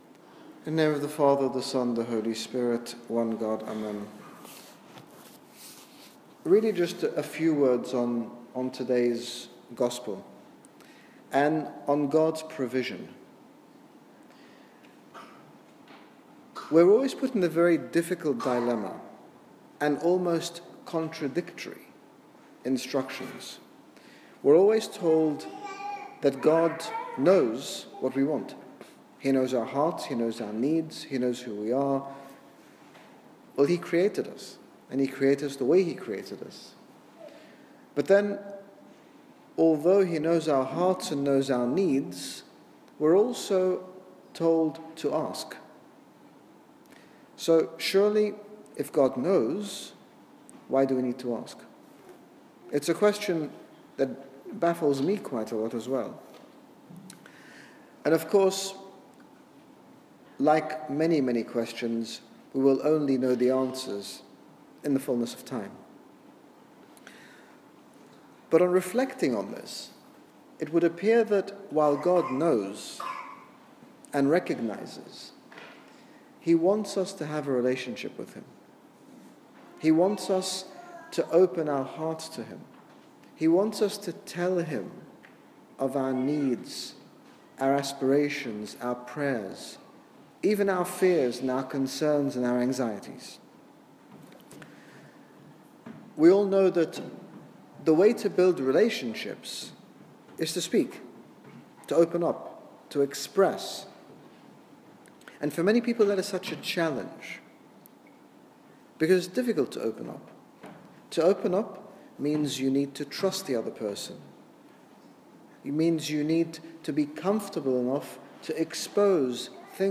In this sermon His Grace Bishop Angaelos talks about God's desire for us all to have a personal relationship with Him, sharing our anxieties, fears, hopes, concerns with Him so that we can benefit from Him as our loving Father.